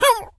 dog_hurt_whimper_howl_08.wav